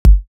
Short Sizzle.wav